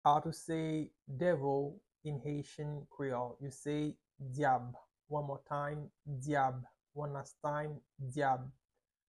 "Devil" in Haitian Creole is "Dyab" - "Dyab" pronunciation by a native Haitian tutor
“Dyab” Pronunciation in Haitian Creole by a native Haitian can be heard in the audio here or in the video below:
How-to-say-Devil-in-Haitian-Creole-Dyab-pronunciation-by-a-native-Haitian-tutor.mp3